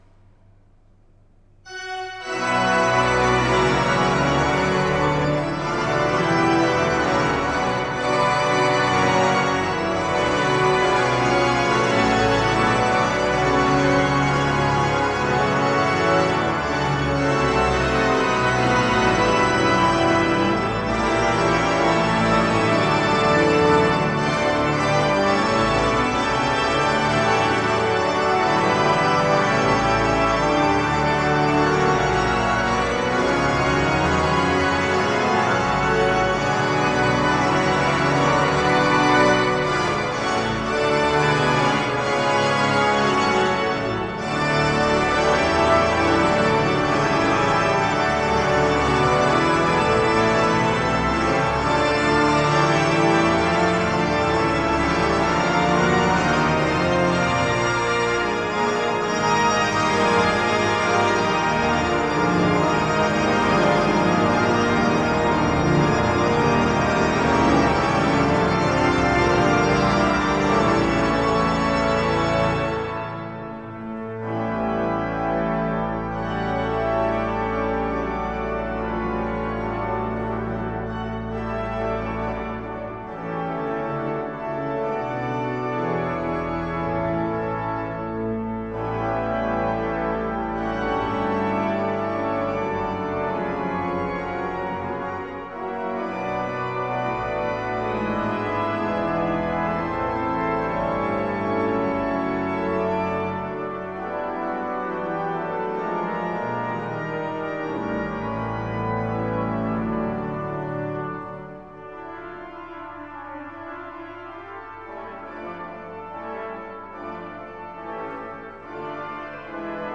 Olomouc, kostel sv. Michala - varhany
Bývalý klášterní kostel dominikánů skrývá velký novodobý nástroj s bohatou historií, umístěný v barokní skříni.